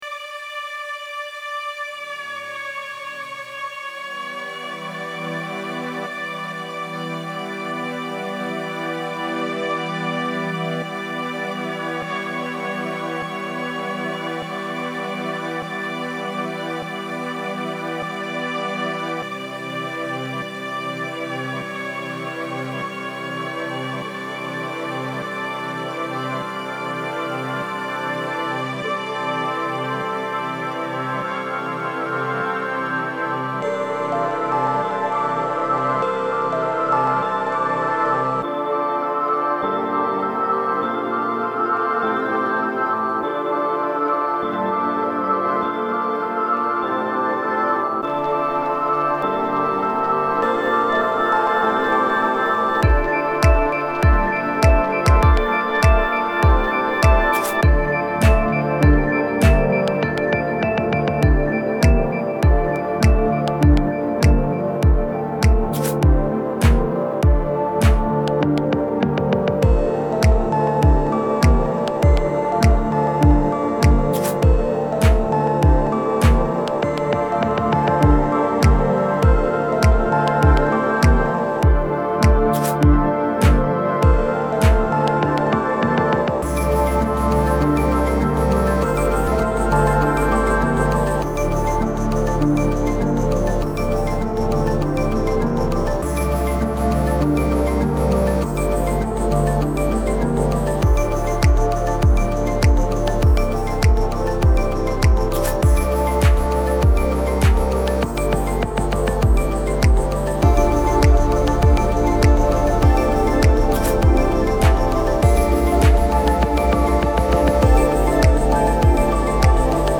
Once sitting down, the viewer will discover the headphones and put them on, becoming more relaxed as the calm music drowns out the chaos outside the fort.
Welcome Home (played on headphones)